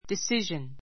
decision disíʒən ディ スィ ジョン 名詞 決定, 結論, 決心; 決断力 関連語 「決める」は decide . make a decision make a decision 決定する, 決める After a long discussion, we came to [reached] a decision.